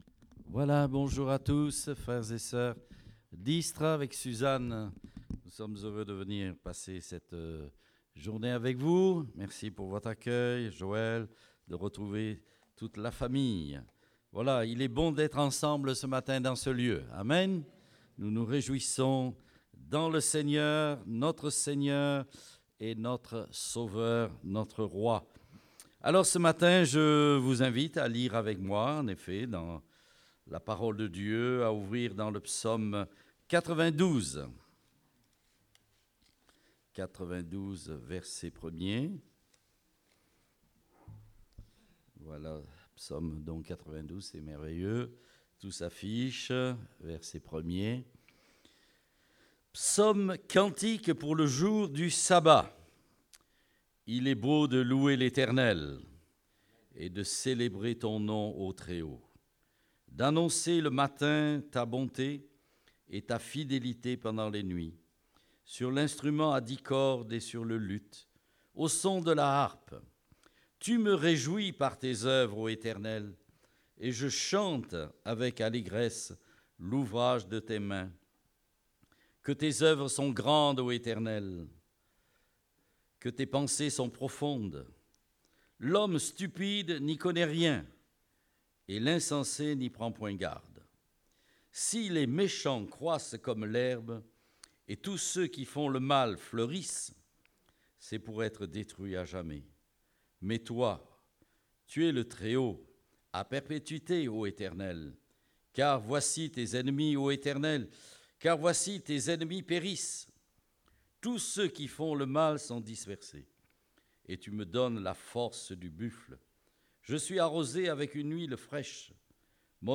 Date : 17 septembre 2017 (Culte Dominical)